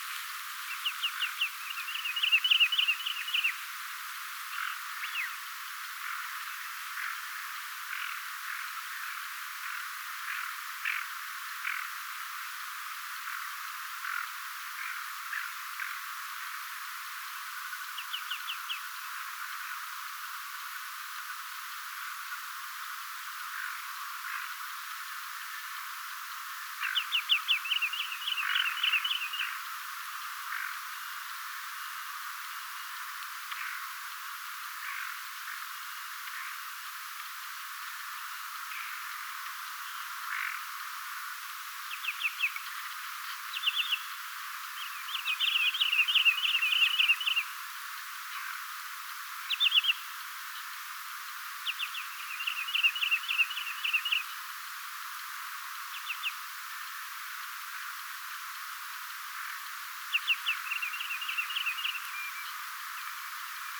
liron laulua, tukkasotka ääntelee
liron_laulua_tukkasotka_aantelee.mp3